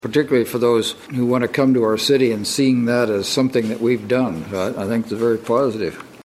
Mayor Mike Dodson said the project is great for Manhattan.